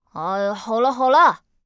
序章与第一章配音资产
c01_3残疾小孩_1.wav